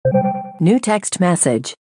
Categoria Mensagem